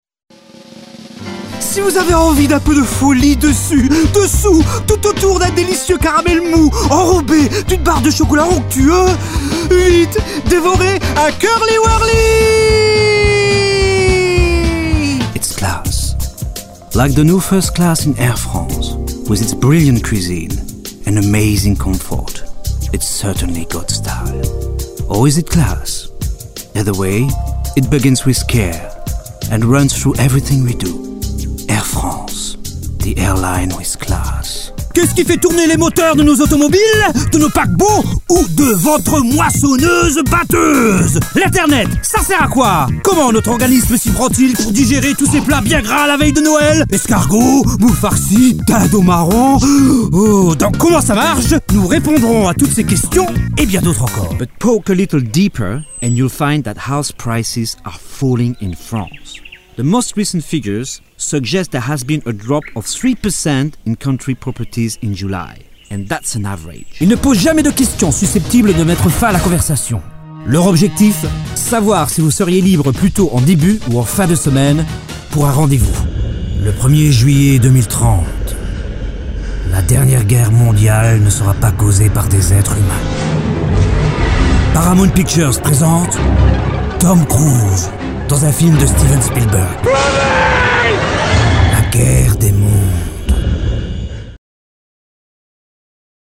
Experienced actor and VO - character specialist.